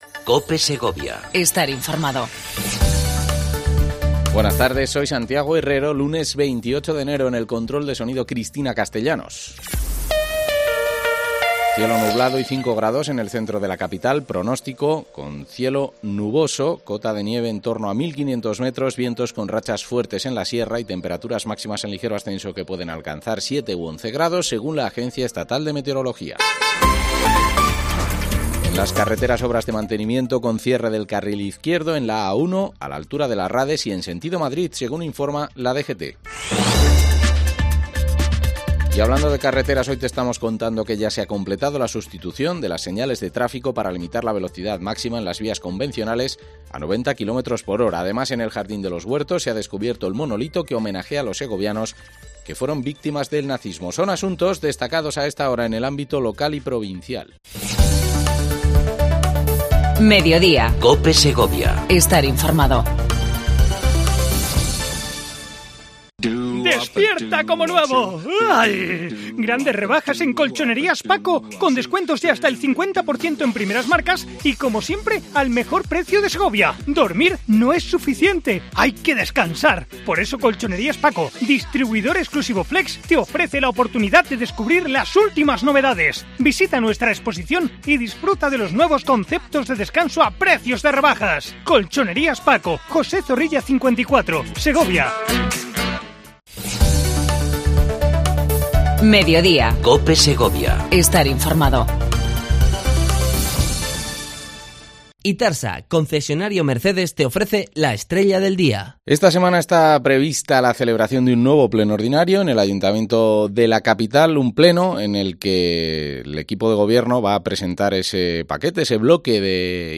Entrevista a Jesús García Zamora, Portavoz del equipo de gobierno, Concejal de Economía y Hacienda. Modificacion presupuestaria e inversiones del 2019